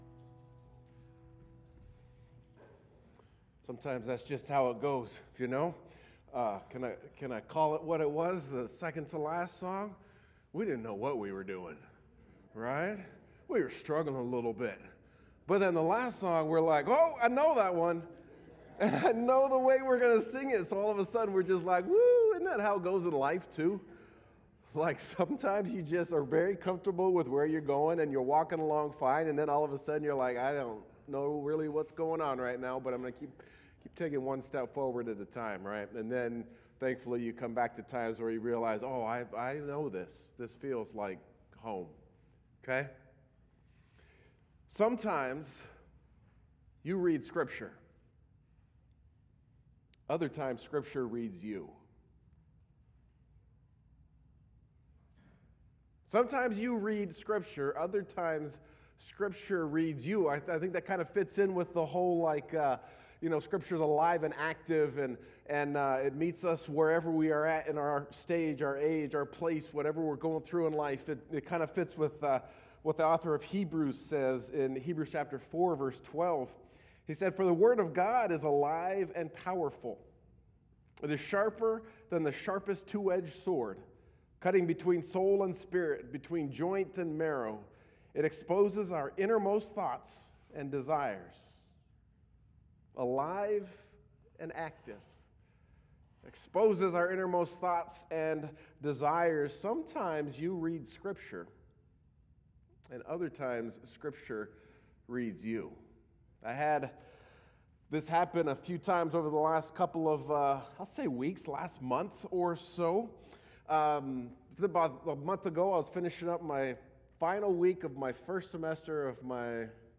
Latest was Sunday Service.